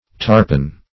Tarpon \Tar"pon\, n. (Zool.)